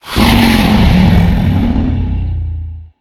dragon_growl4.ogg